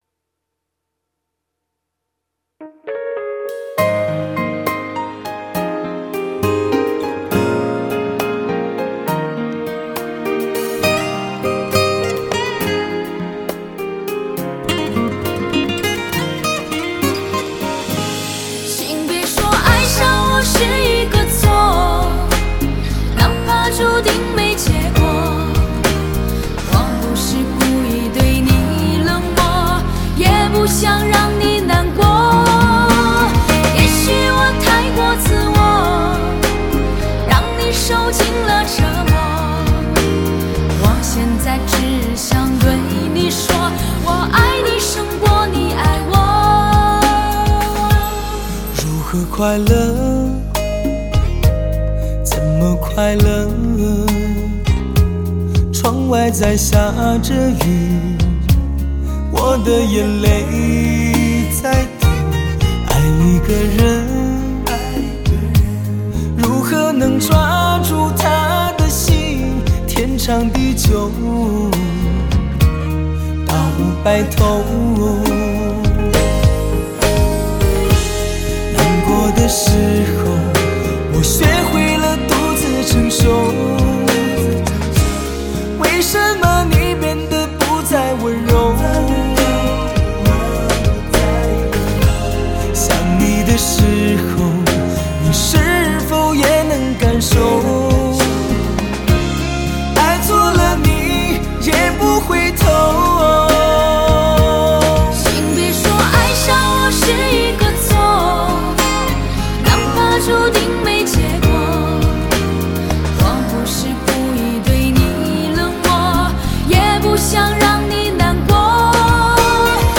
乐坛新势力 完美对唱 首首主打